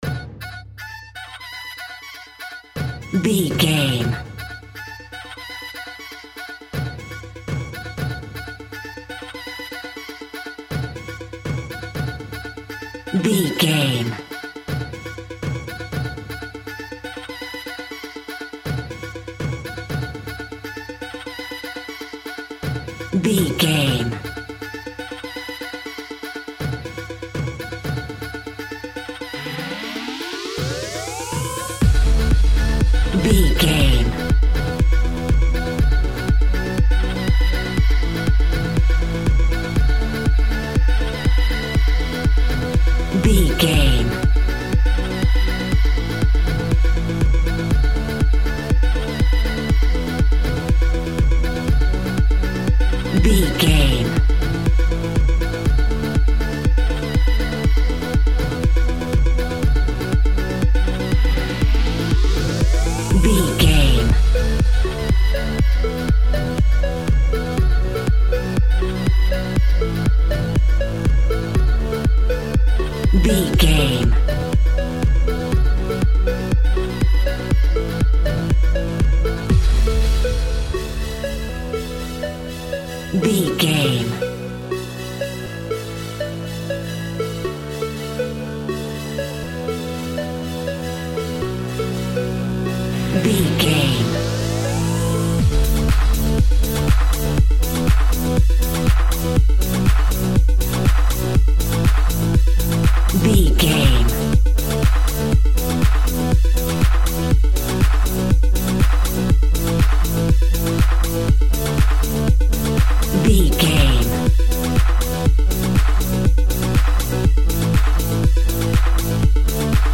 Aeolian/Minor
F#
groovy
uplifting
driving
energetic
synthesiser
drum machine
house
electro dance
dance instrumentals
synth leads
synth bass
upbeat